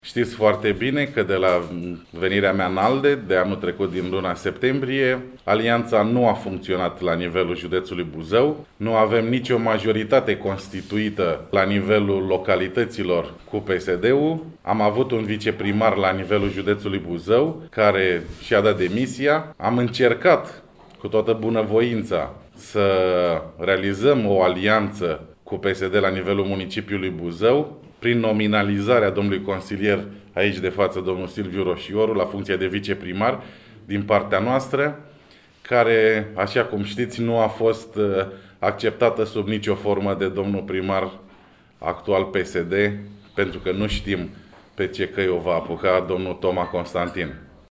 În cadrul unei conferințe de presă s-a discutat printre altele și despre rezultatele obținute de către organizația județeană ALDE, care după cum a declarat Toma Petcu, au demonstrat faptul că la Buzău, echipa formată s-a dovedit a fi una închegată, cu potențial pe viitor.